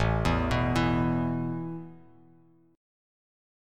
Asus2 chord